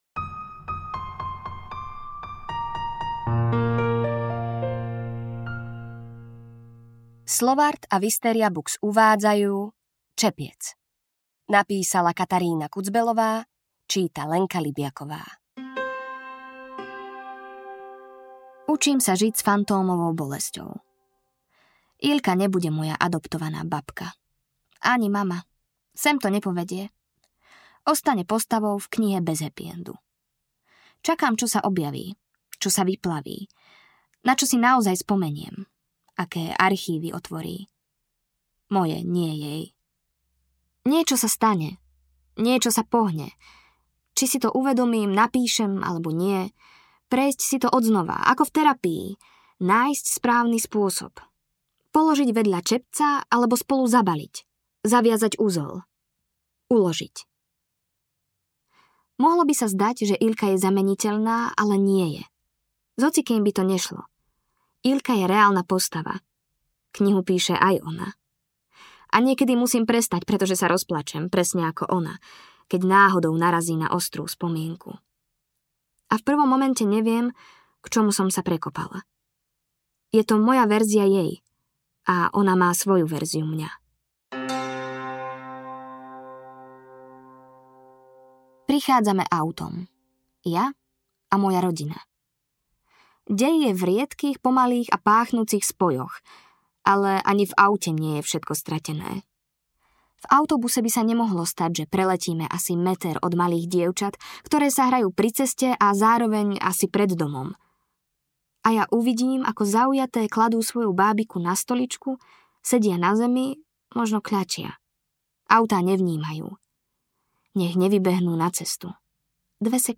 Čepiec audiokniha
Ukázka z knihy